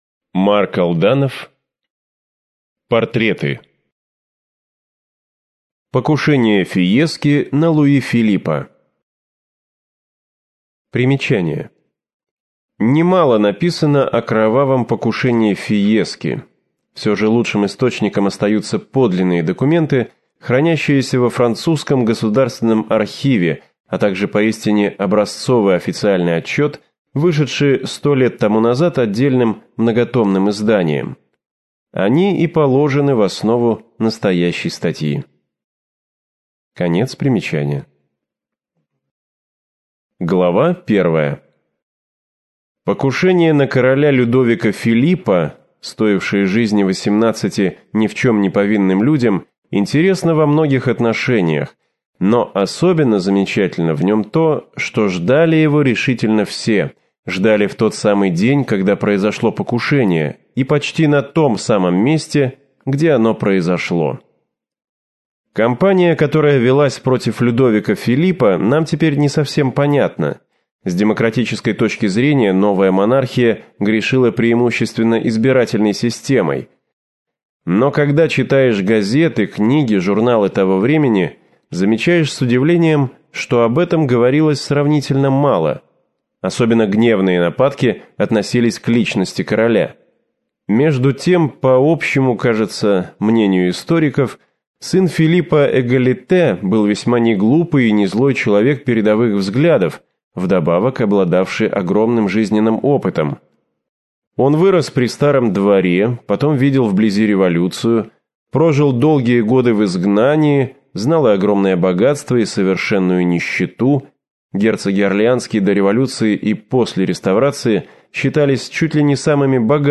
Аудиокнига Покушение Фиески на Луи Филиппа. Убийство президента Карно. Бург императора Франца Иосифа. Мейерлинг и кронпринц Рудольф | Библиотека аудиокниг